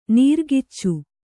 ♪ nīrgiccu